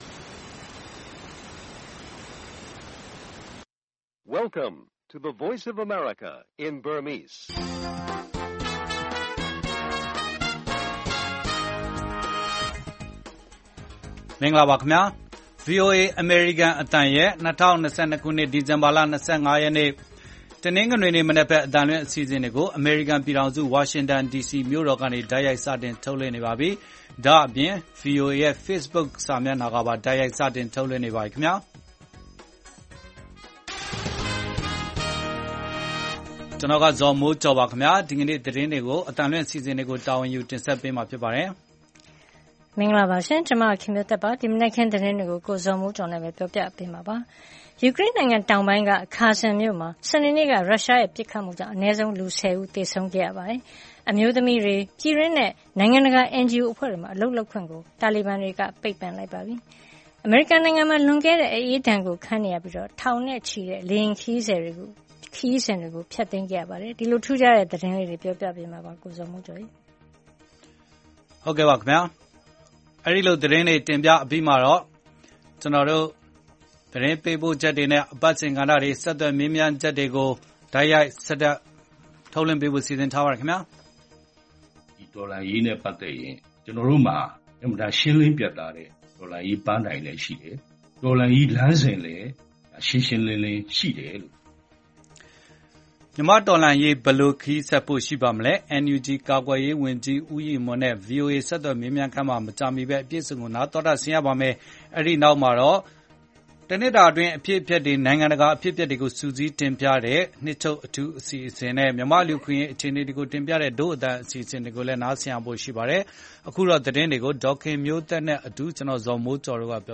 အမေရီးကားမှာရာသီဥတုဆိုးရွားတဲ့အတွက်လေယဉ်ခရီးစဉ်တွေထောင်နဲ့ချီပါယ်ဖျက်။ ပါရီမြို့တော်မှာ ကဒ်ဆန္ဒပြသူတွေနဲ့ရဲတွေထပ်မံအထိကရုန်းဖြစ်ပွား။ အာဖဂန်မှာ အမျိုးသမီးတွေကို NGO တွေမှာအလုပ်လုပ်ခွင့် ပိတ်ပင်။ NUG ကာကွယ်ရေးဝန်ကြီးနဲ့ဆက်သွယ်မေးမြန်းချက်၊ ဒို့အသံ နဲ့ တနှစ်တာနိုင်ငံတကာအဖြစ် အပျက်စုစည်းတင်ပြချက်